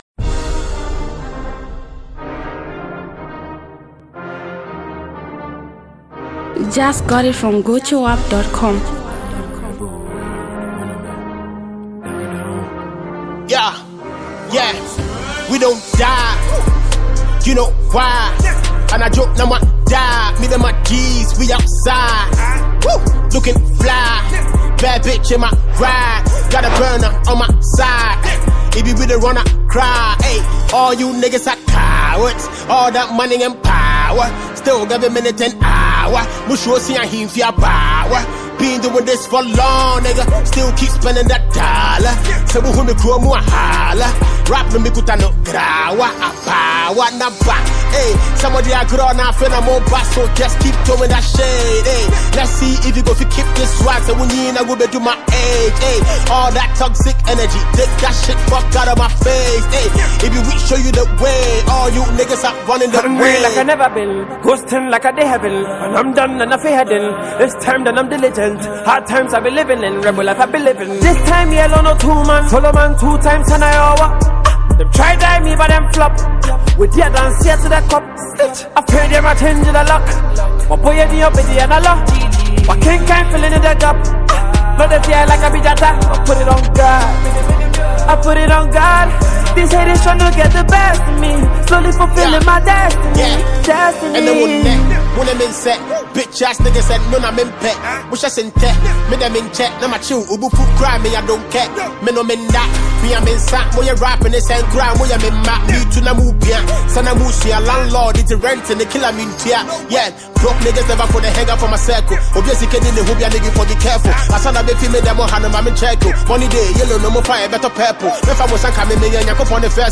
street-anthem